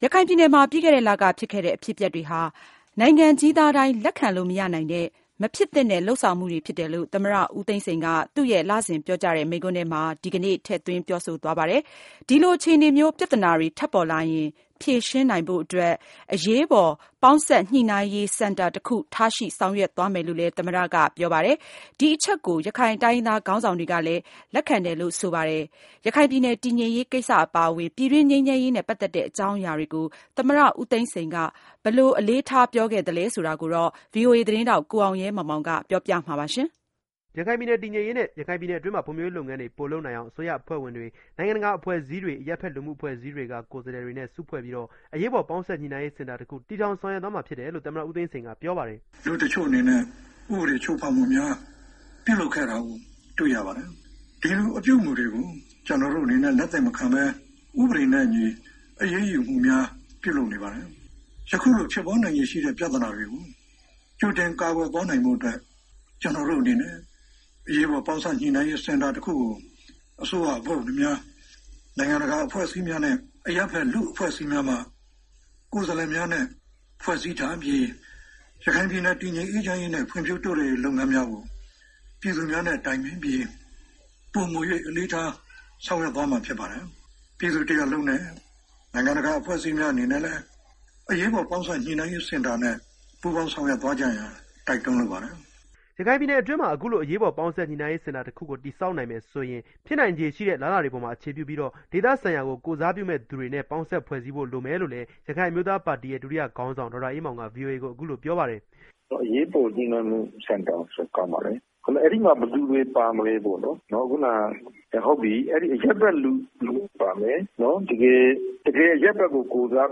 မြန်မာသမ္မတဦးသိန်းစိန် မေလ မိန့်ခွန်း